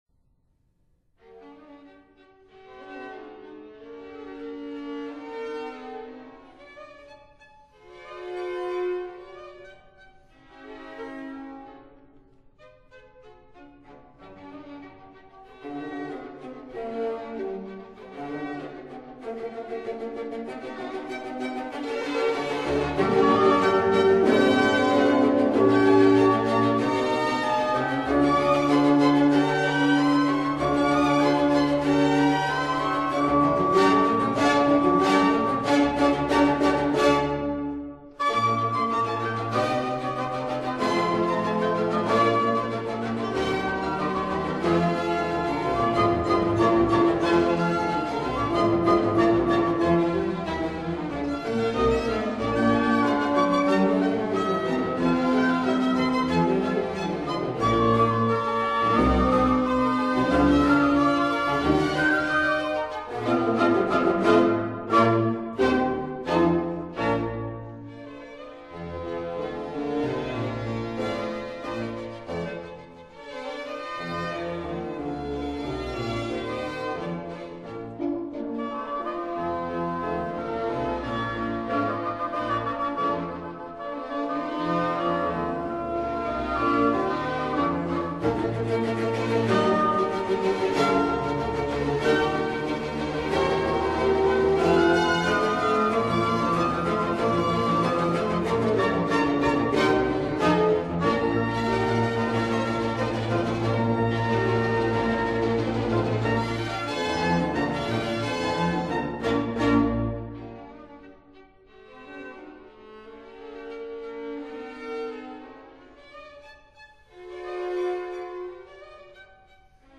Symphony in G minor
- Vivace    [0:06:44.82]